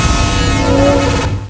old_teleporter2.wav